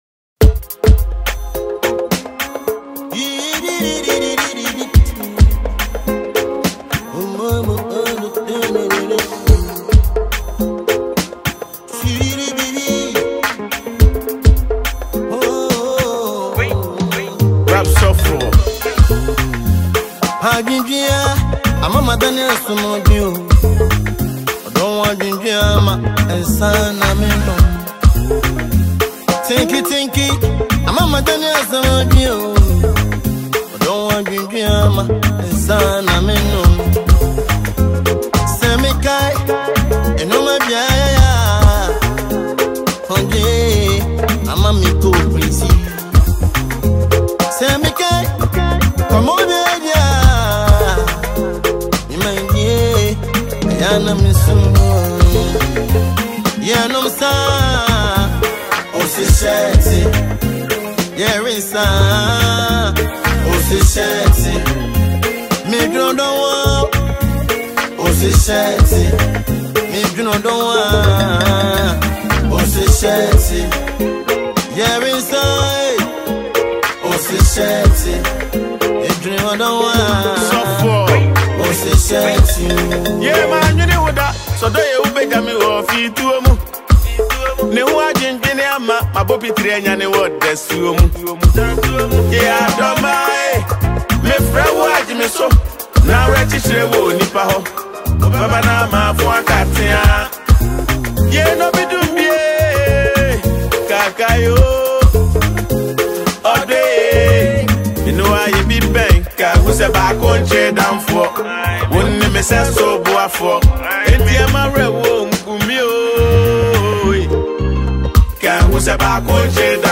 This timeless masterpiece blends sweet highlife melodies
and poetic Akan storytelling
smooth, emotional vocals